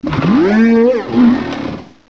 sovereignx/sound/direct_sound_samples/cries/cyclizar.aif at 5119ee2d39083b2bf767d521ae257cb84fd43d0e
cyclizar.aif